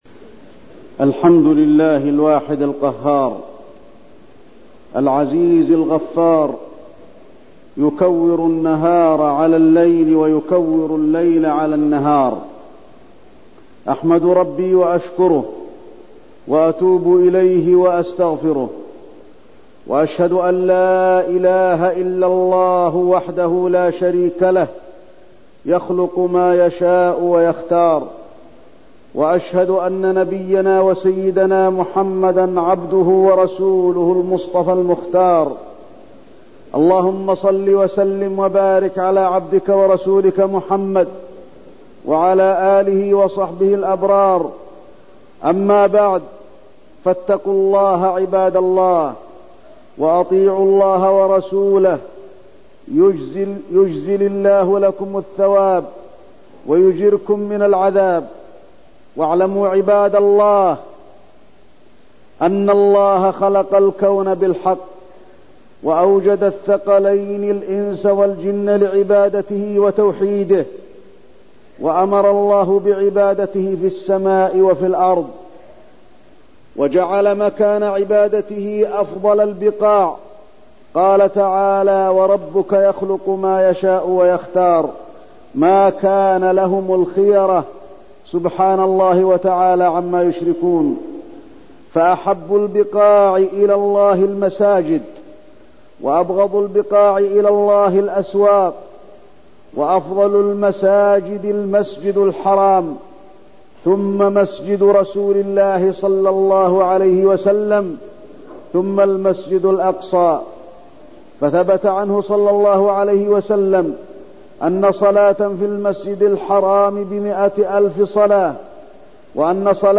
تاريخ النشر ٢٦ محرم ١٤١٤ هـ المكان: المسجد النبوي الشيخ: فضيلة الشيخ د. علي بن عبدالرحمن الحذيفي فضيلة الشيخ د. علي بن عبدالرحمن الحذيفي فضل بيت المقدس وما حدث له من احتلال